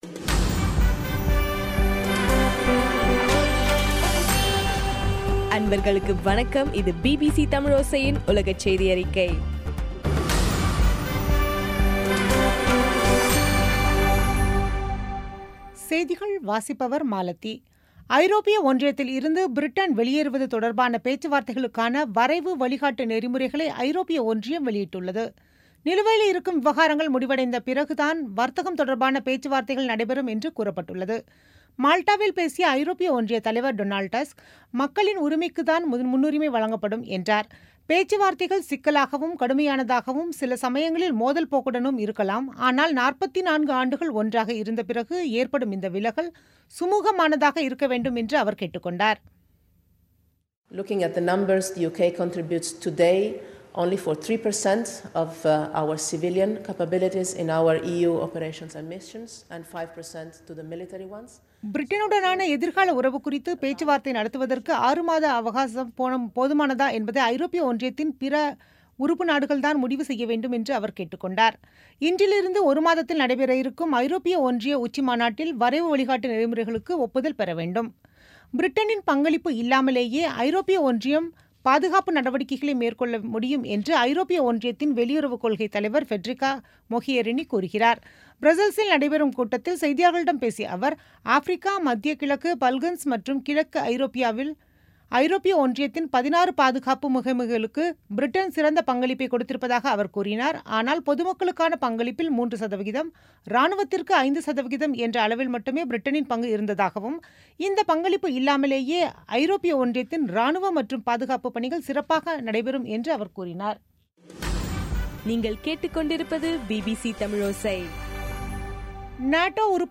பிபிசி தமிழோசை செய்தியறிக்கை (31/03/2017)